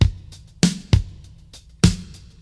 • 99 Bpm Old School Breakbeat Sample B Key.wav
Free drum groove - kick tuned to the B note. Loudest frequency: 1348Hz
99-bpm-old-school-breakbeat-sample-b-key-Btb.wav